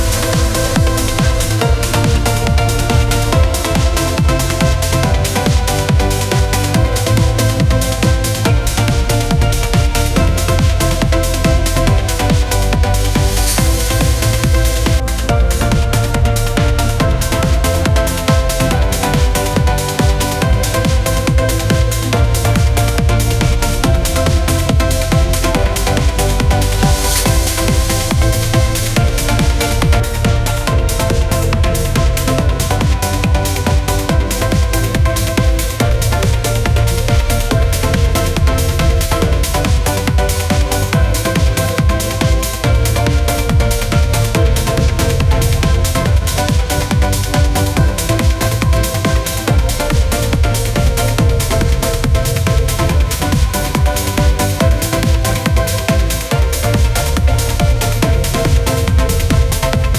MusicGen-Chord fine-tuned on 90s trance
"bpm": 140,
"time_sig": "4/4",
"text_chords": "A:min9 G:min9 F:maj7 E:min9",